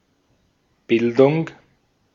Ääntäminen
US : IPA : [ˌɛ.dʒə.ˈkeɪ.ʃən]